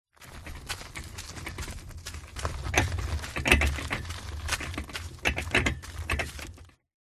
Звуки сада
Катит тачку по ухабистой тропке